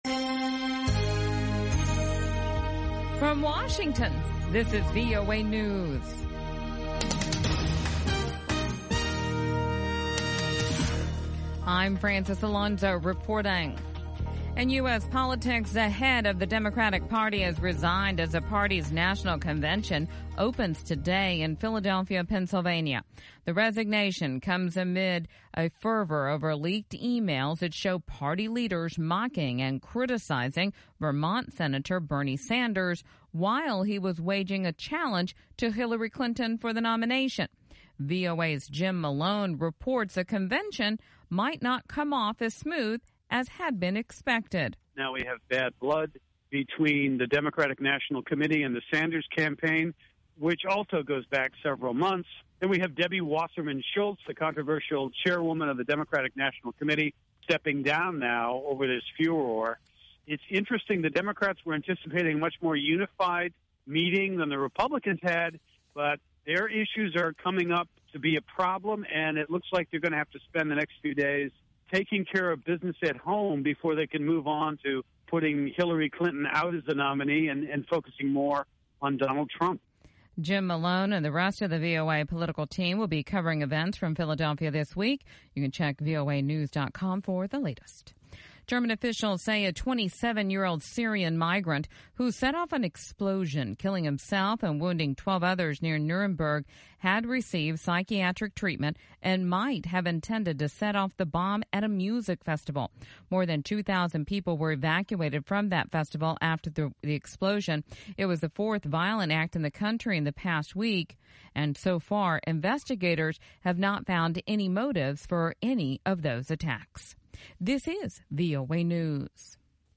VOA English Newscast 1500 UTC July 25, 2016